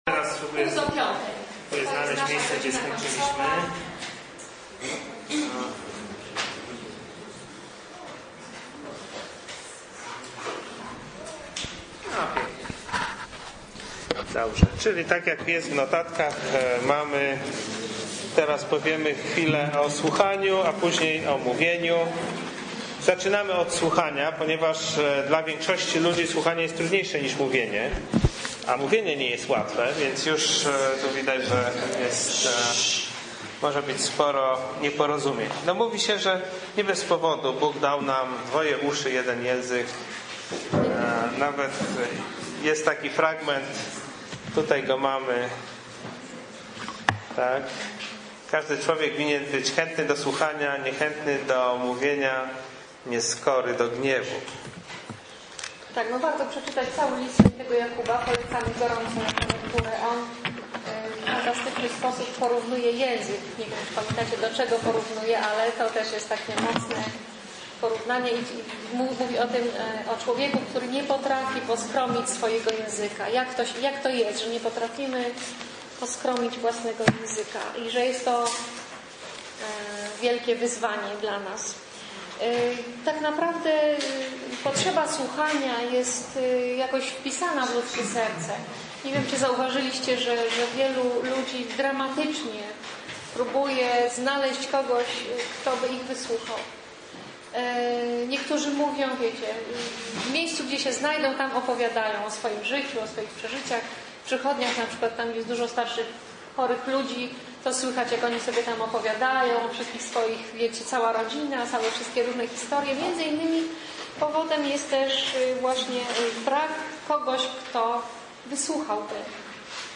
Sobota Konferencja cz. I